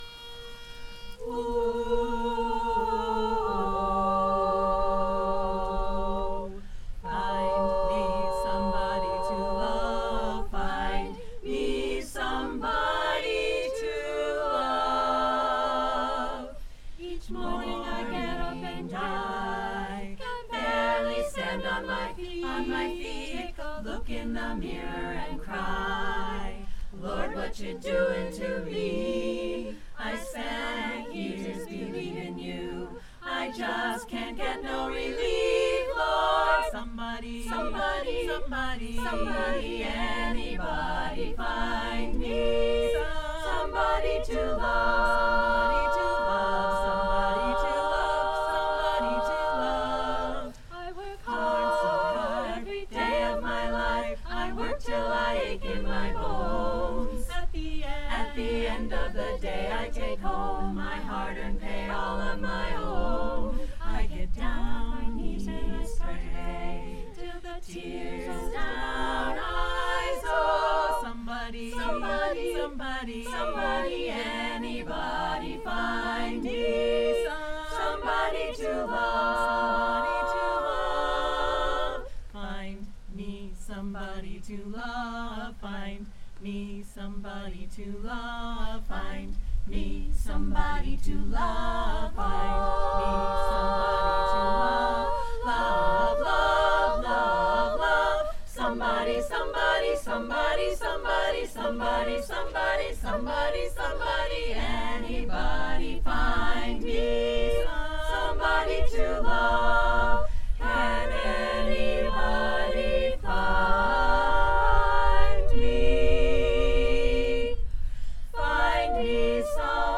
Join us for any open rehearsal and experience the thrill of barbershop singing.
Eekapella at Historic Northampton